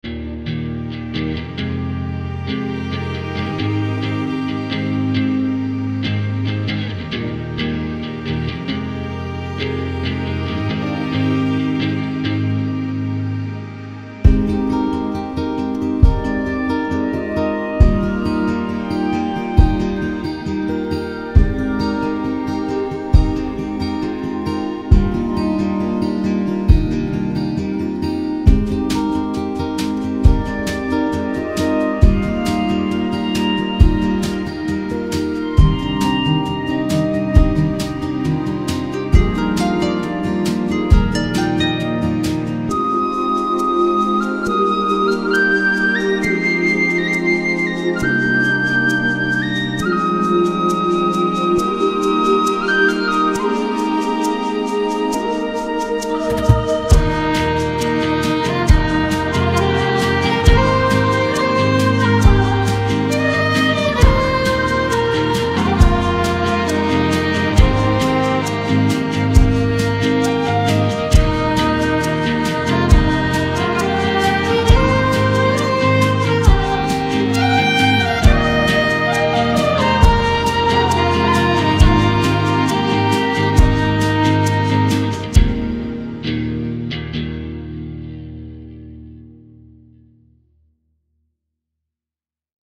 Sounds Melancholic first but then turning into hope.